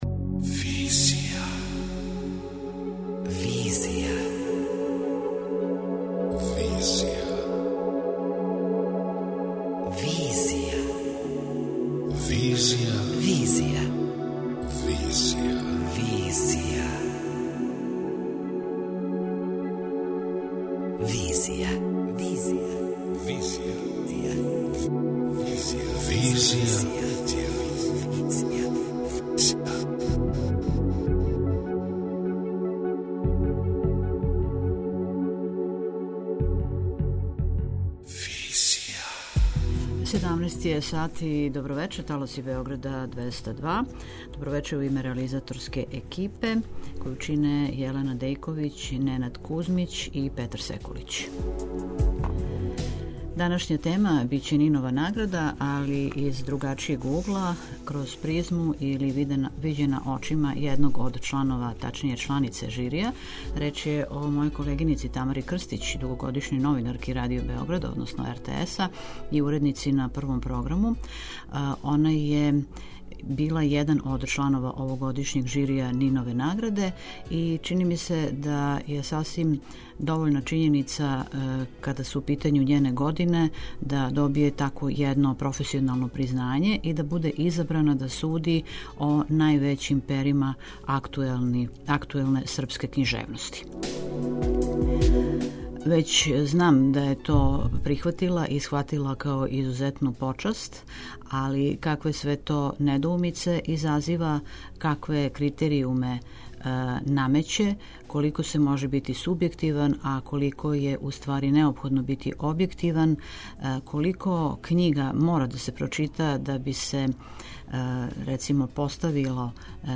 преузми : 28.65 MB Визија Autor: Београд 202 Социо-културолошки магазин, који прати савремене друштвене феномене.